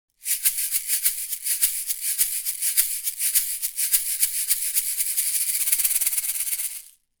Der erste Eindruck klingt vollmundig und warm – ganz so wie ich es von einer Röhre erwartet hätte.
Klangbeispiele zum t.bone SCT 700
Shaker
• Röhrenmikrofon
tbone_sct700_shaker.mp3